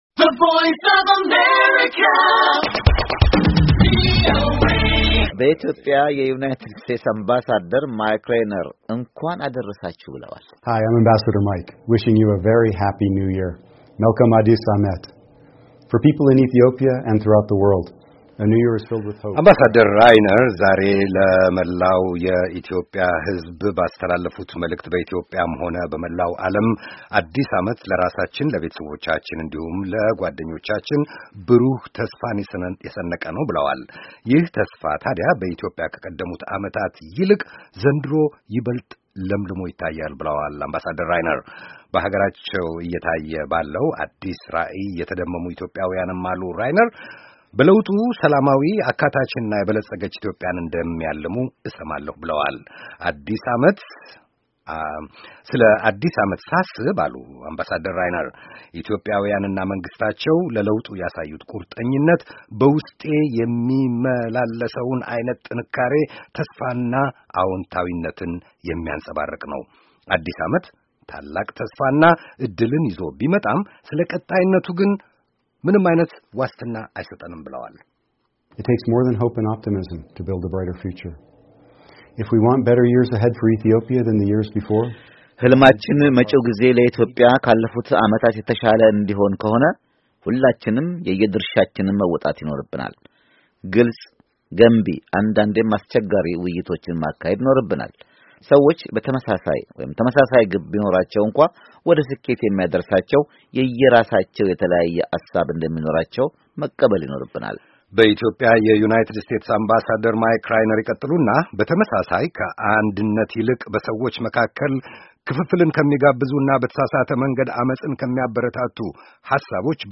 በኢትዮጵያ የዩናይትድ ስቴትስ አምባሳደር ማይክ ራይነር ለአዲስ ዓመት ካስተላለፉት የመልካም ምኞት መግለጫና መልዕክት የተቀነጨበ ነው የሰማችሁት፤ ይዘናቸዋል።